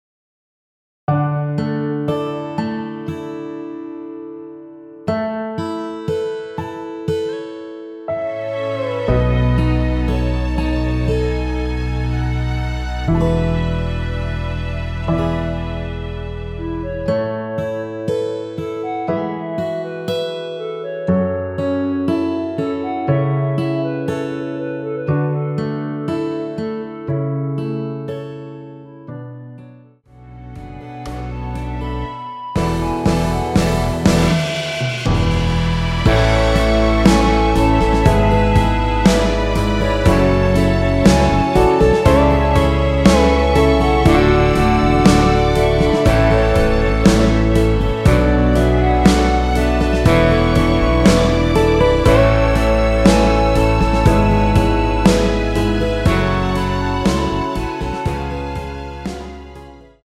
원키에서(+5)올린 멜로디 포함된 MR입니다.
앞부분30초, 뒷부분30초씩 편집해서 올려 드리고 있습니다.